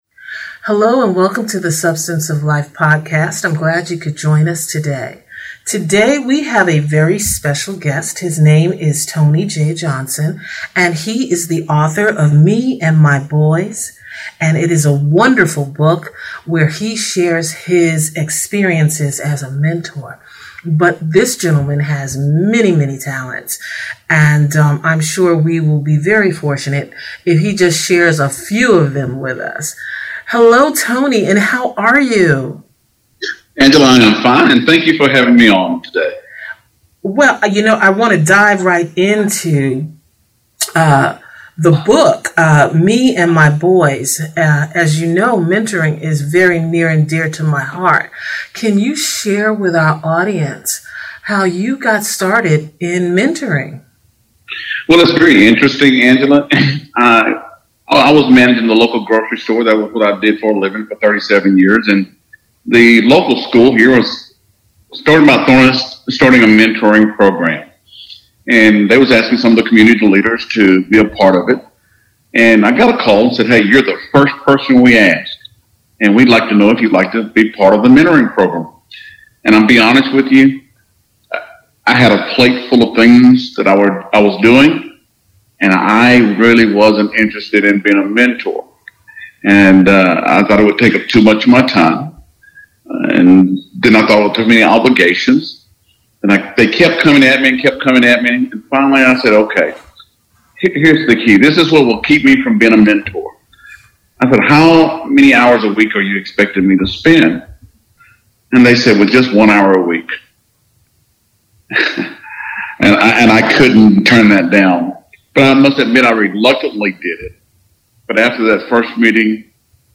Me & My Boys Podcast is a conversation for parents, community leaders, and anyone involved in organizations that serves the positive mentoring of children.